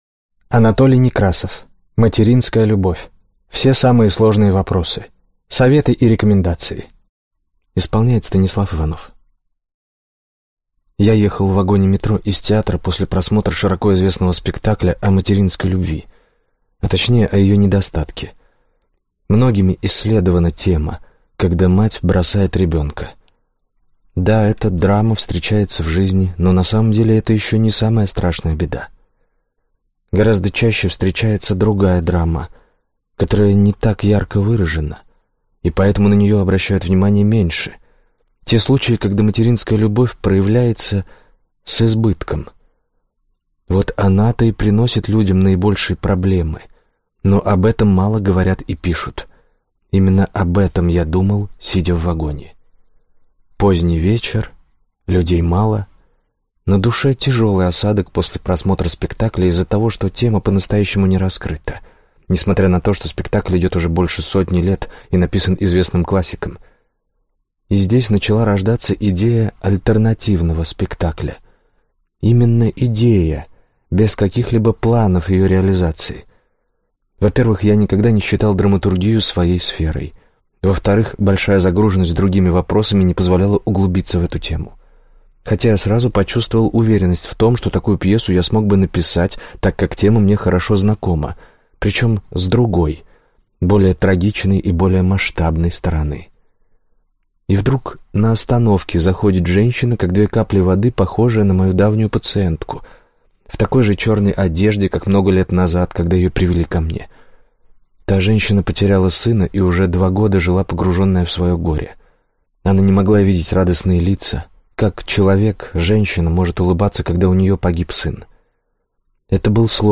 Аудиокнига Материнская любовь. Все самые сложные вопросы. Советы и рекомендации | Библиотека аудиокниг